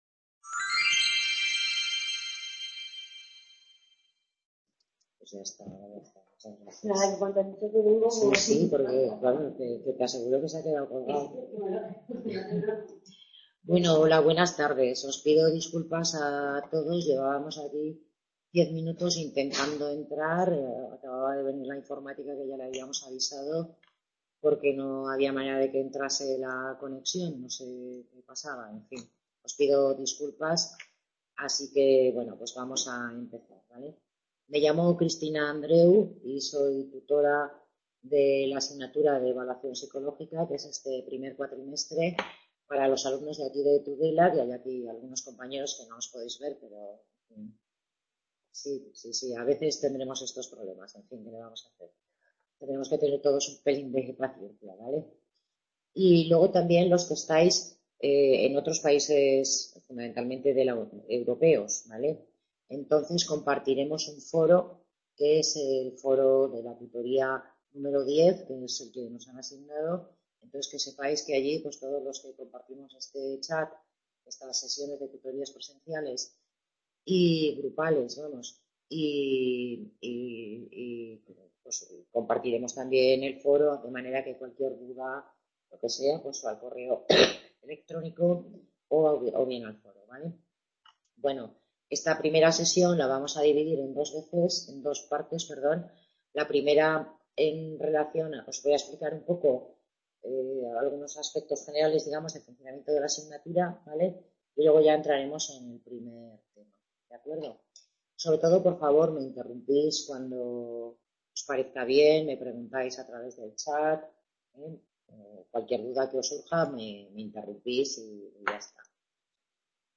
Tutoría grupal